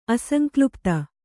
♪ asaŋklupta